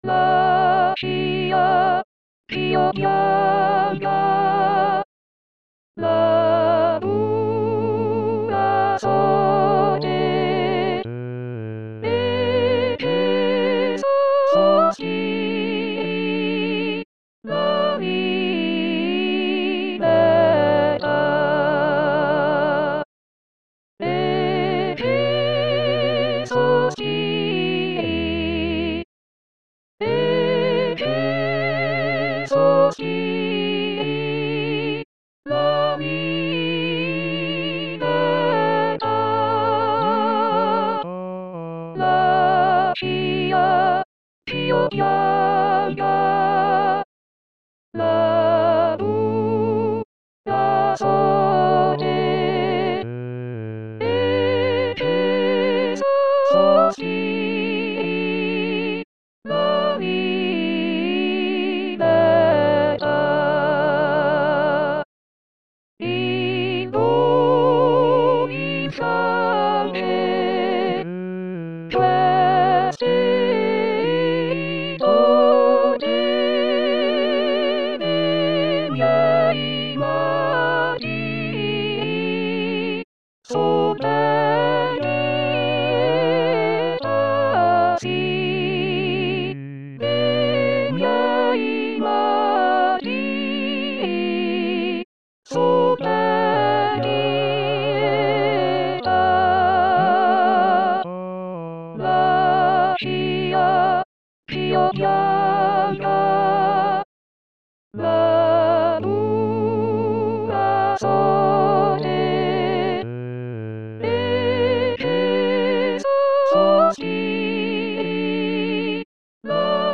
Atelier soprani
Pourquoi "atelier", parce qu'en plus des habituels fichiers contenant soit votre seule voix, soit votre voix accompagnée des autres voix, vous y trouverez aussi un ensemble de "pièces détachées" permettant de travailler certaines séquences d'un chant.
Voix seule  Voix accompagnée